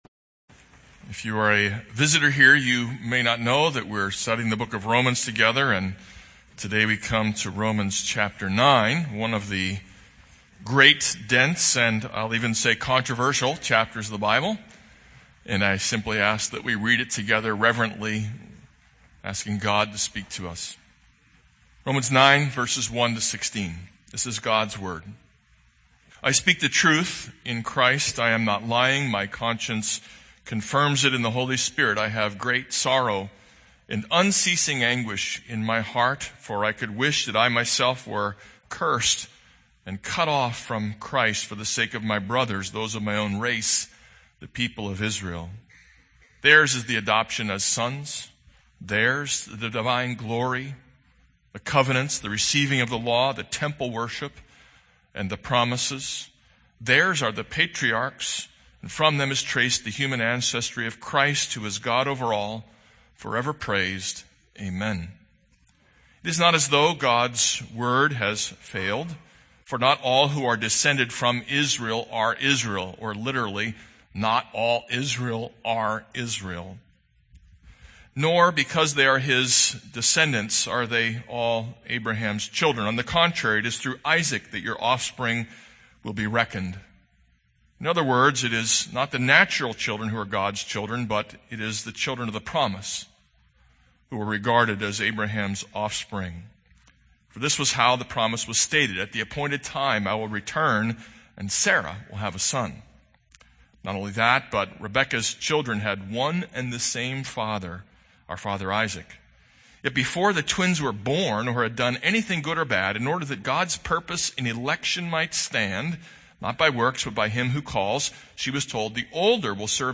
This is a sermon on Romans 9:1-16.